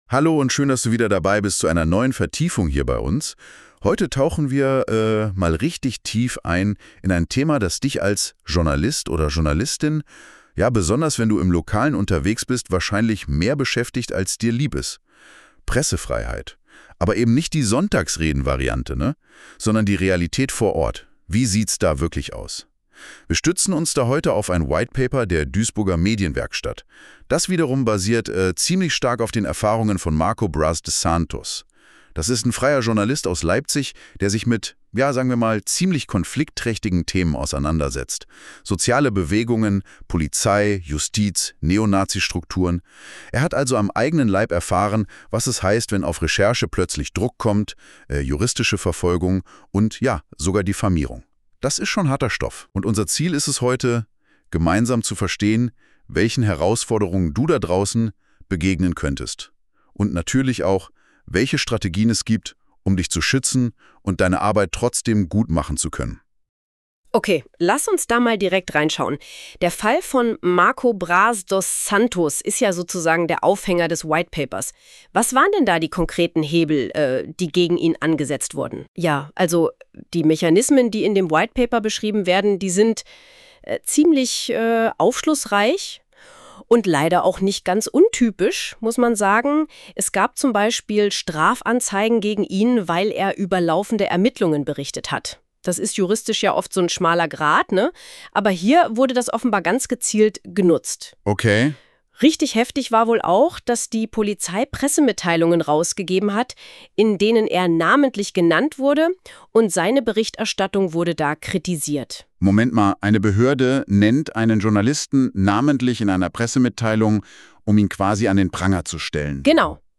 Unsere KI‑Hosts diskutieren, welche Herausforderungen sich für Lokaljournalist:innen ergeben – von SLAPP Klagen bis zu polizeilicher Beeinflussung.